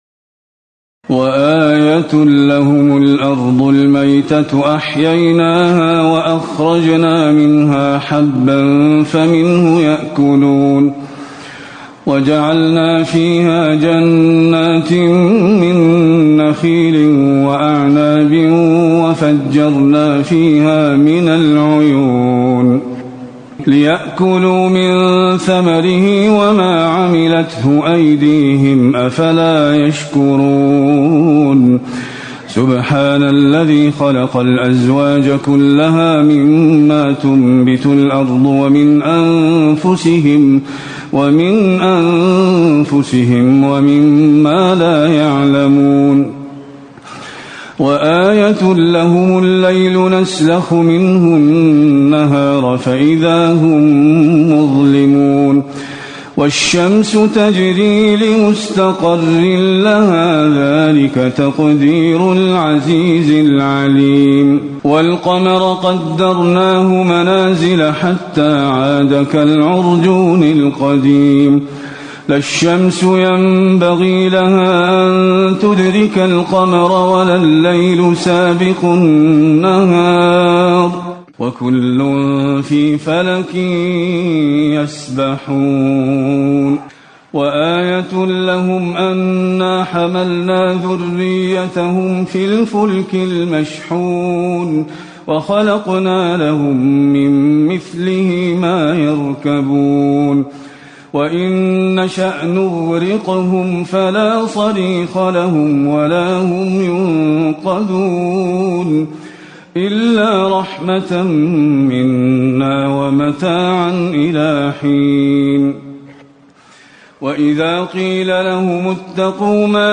تراويح ليلة 22 رمضان 1437هـ من سور يس (33-83) والصافات (1-132) Taraweeh 22 st night Ramadan 1437H from Surah Yaseen and As-Saaffaat > تراويح الحرم النبوي عام 1437 🕌 > التراويح - تلاوات الحرمين